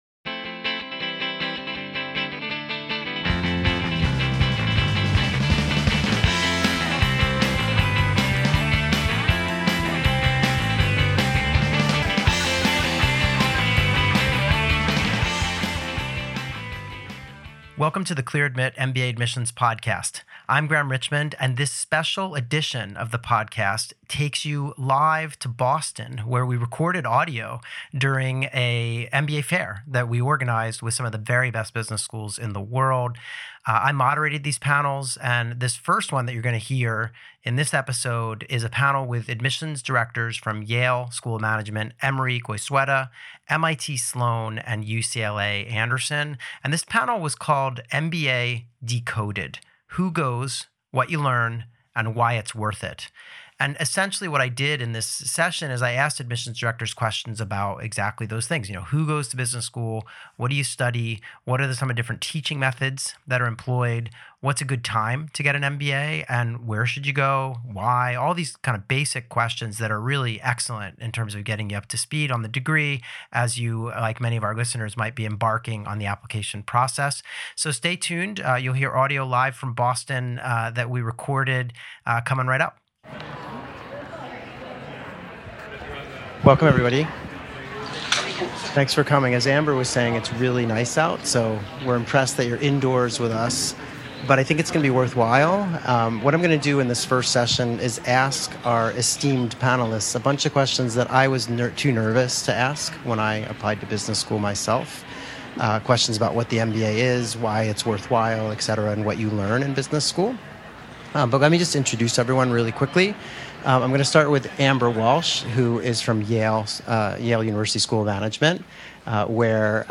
Whether you're just beginning to explore the MBA path or are deep into your application journey, this panel from the 2025 Clear Admit MBA Fair is designed to break down the essentials of business school and answer the questions every prospective student has—but might be too afraid to ask.